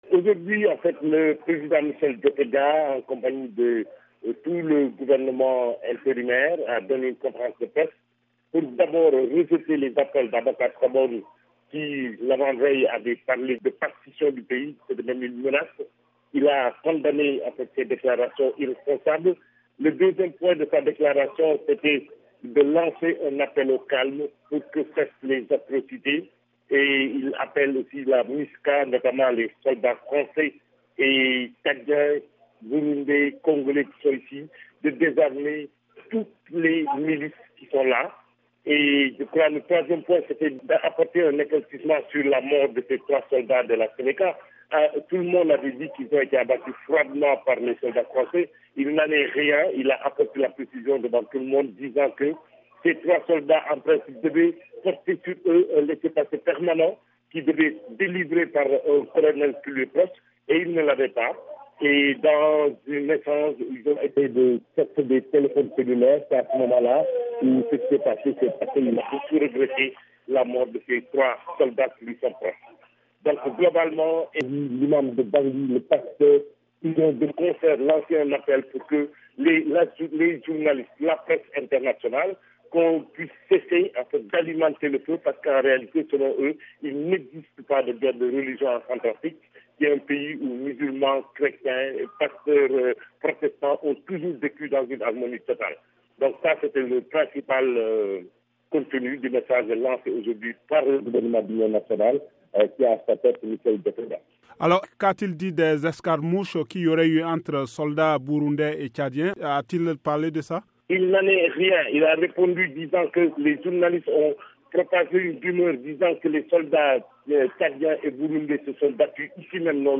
Ecoutez notre envoyé spécial